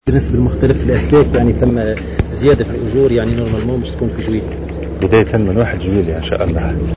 أكد وزير الداخلية الهادي مجدوب في تصريح لمراسل "الجوهرة أف أم" اليوم على هامش زيارته لولاية جندوبة أنه سيتم يوم 1 جويلية المقبل الشروع في صرف الزيادات في أجور الأمنيين.